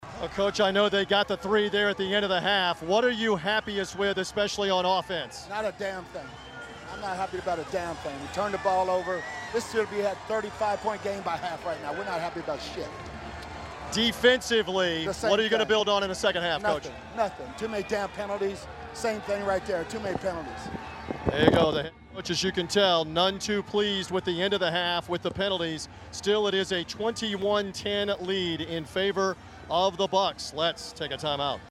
Arians Was Livid At Halftime, Goes On Tirade On Bucs Radio Network